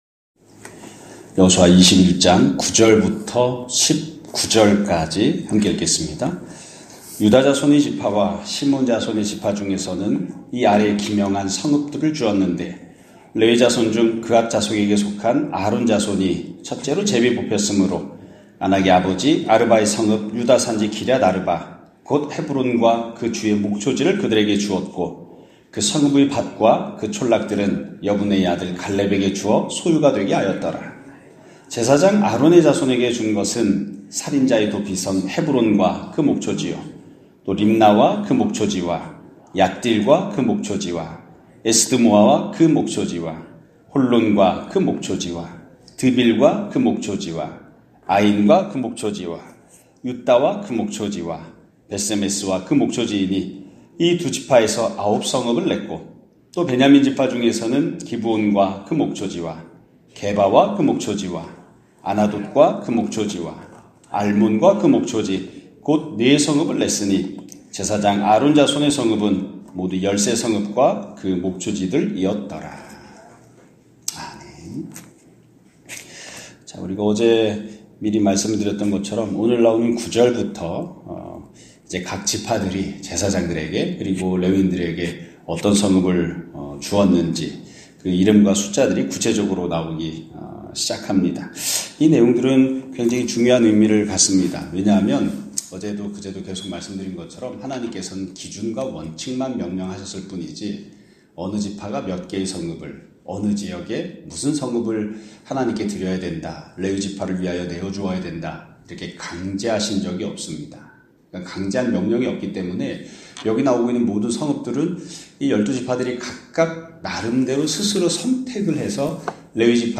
2025년 1월 21일(화요일) <아침예배> 설교입니다.